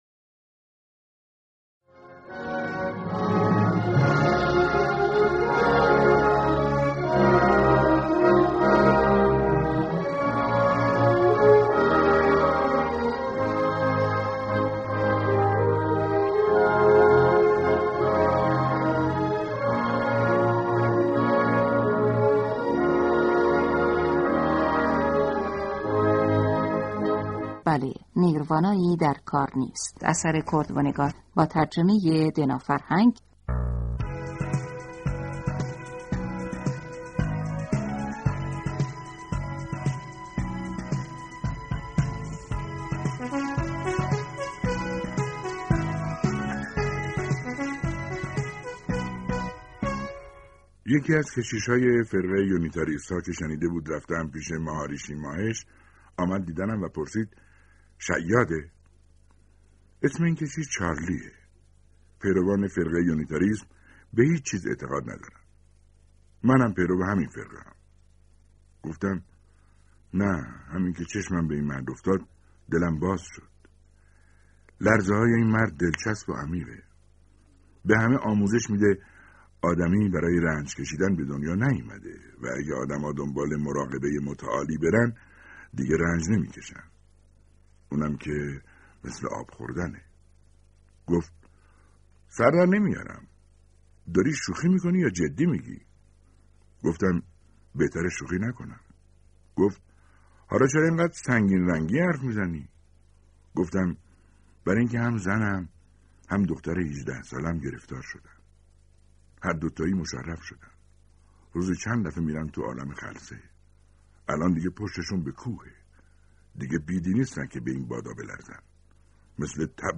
داستان کوتاه: بله، نیروانایی در کار نیست + همراه با فایل داستان صوتی / کورت وونه‌گات جونیور